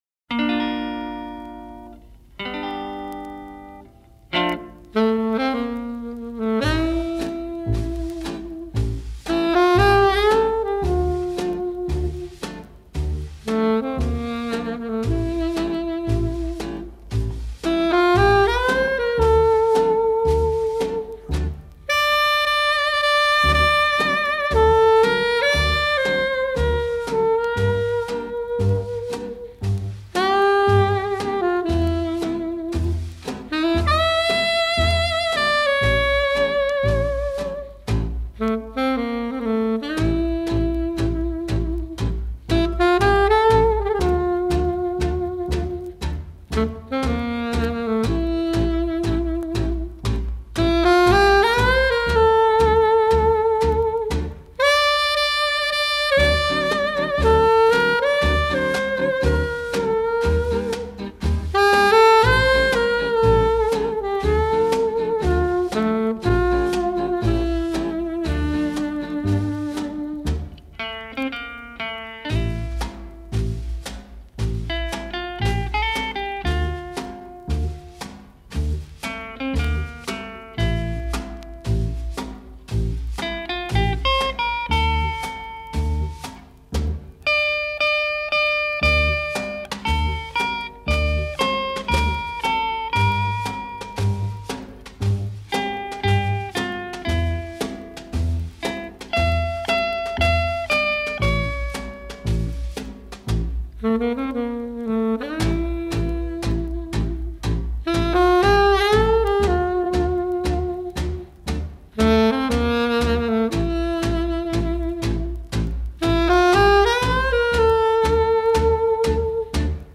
танго
соло сакс и оркестр